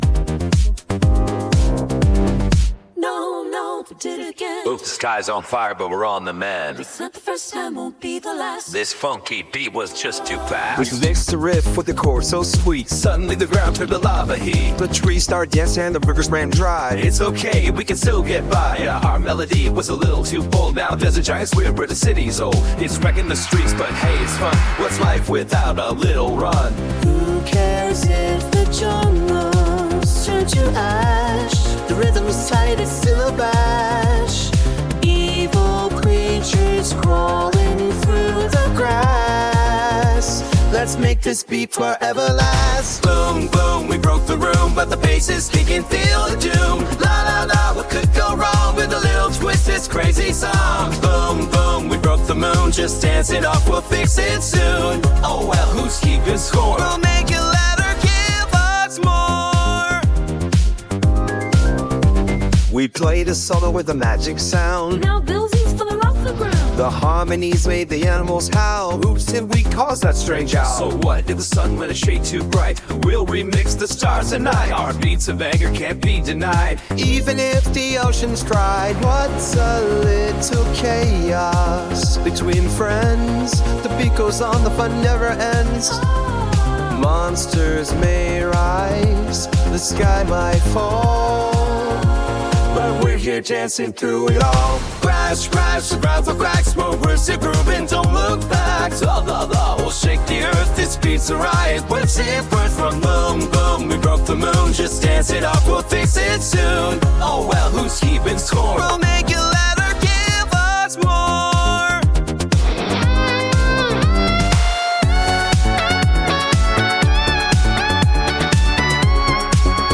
• Nobody sung a single word
• Every single byte in the audio file is synthetically generated by models
That said, if four sound effects had not been included, which only consisted of eight seconds total of audio that was added to the AI-generated tracks, the entire song would have been composed by models.